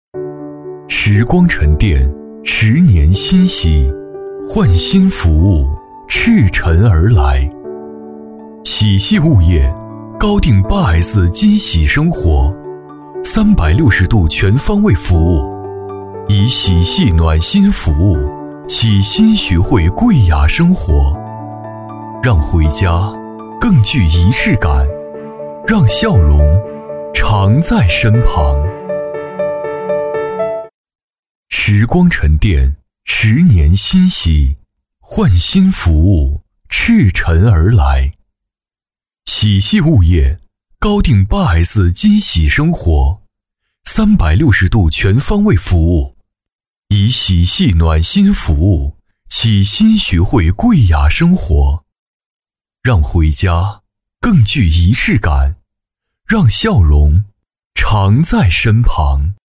男C16-宣传 - 物业
男C16-年轻质感 年轻舒缓
男C16-宣传 - 物业.mp3